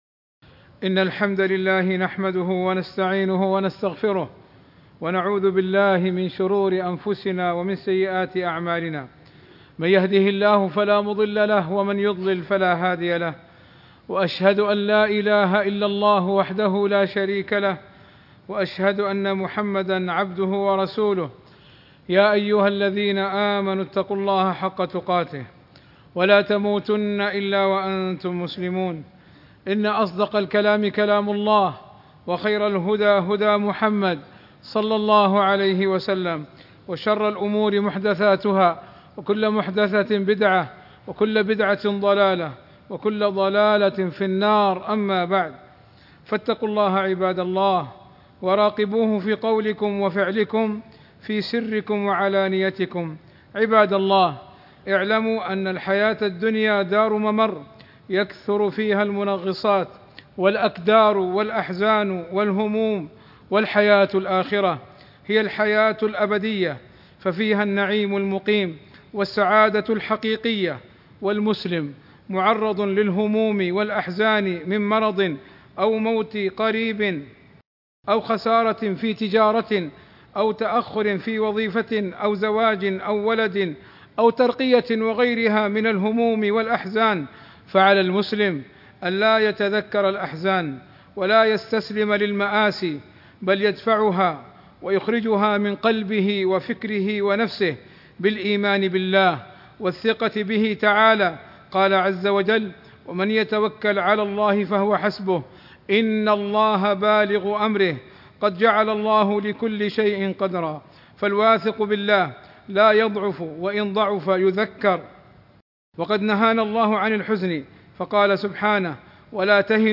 خطب الجمعة المدة